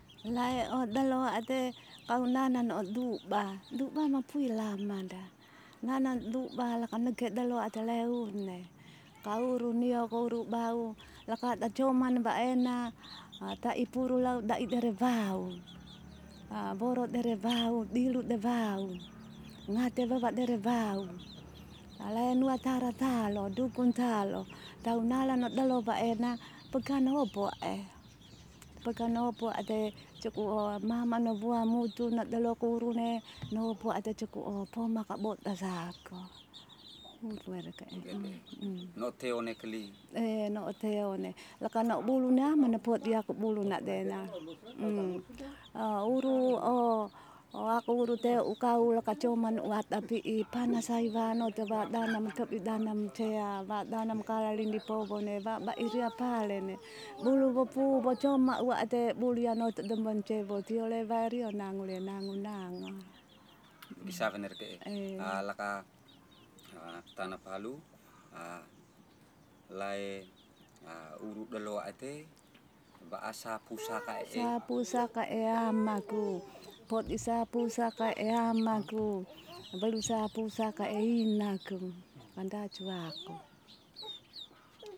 Genre: Medicine/huru.
digital wav file recorded at 48 khz/24 bit
Recording made in kampong Nua hika, Uwa, of woman from Hona, speaking with Hona dialect.